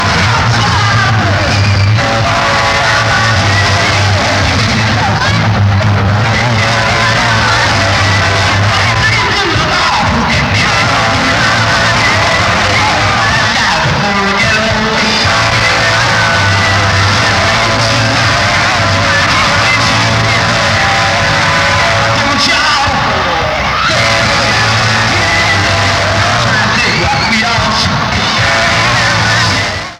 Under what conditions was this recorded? Format/Rating/Source: CD - D - Audience Comments: Rare concert material, very poor sound quality. Rochester '67 * Compression added to enhance the sound quality